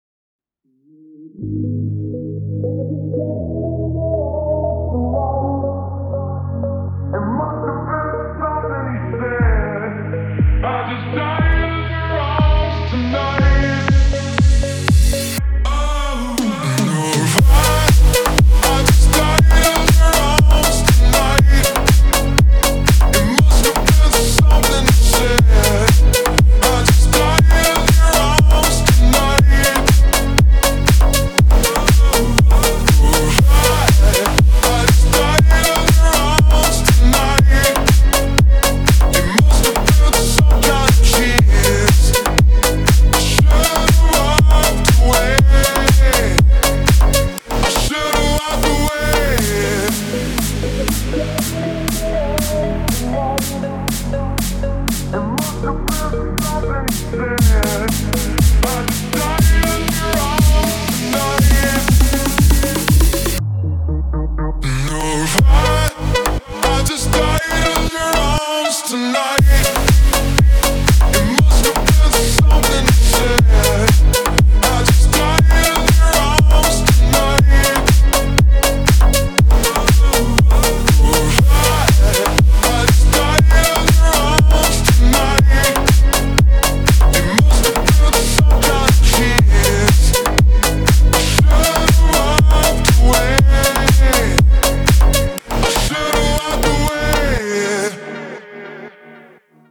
Музыка для тренировок